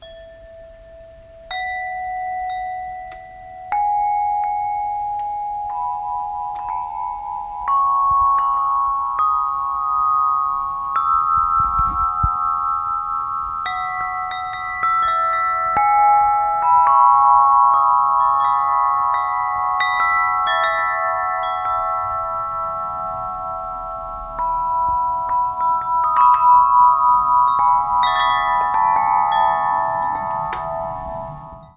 Laděná zvonkohra kovová extra 97 cm
černá kovová zvonkohra
excelentní laděný zvuk
nahrávka MP3 je kapesním rekordérem, amatérská, ale reálná, přímo z prodejny, ze vzdálenosti cca 30 cm
zvonkohra.mp3